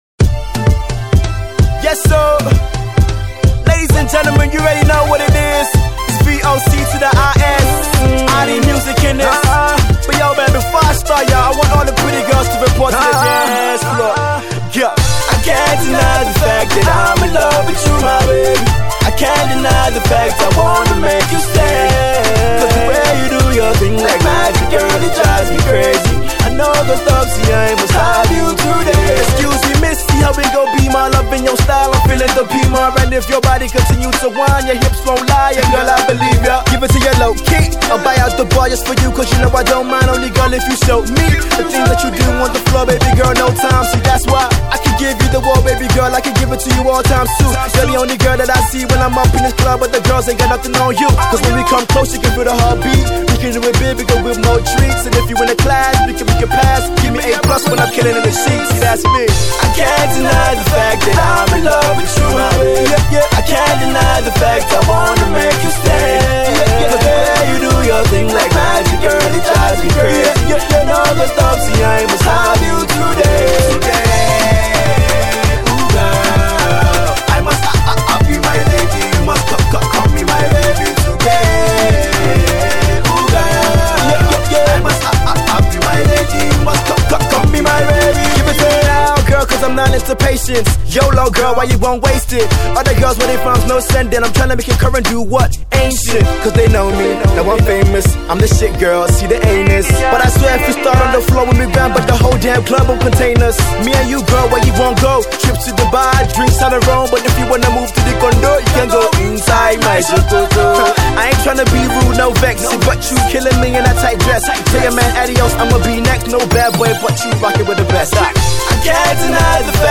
rapper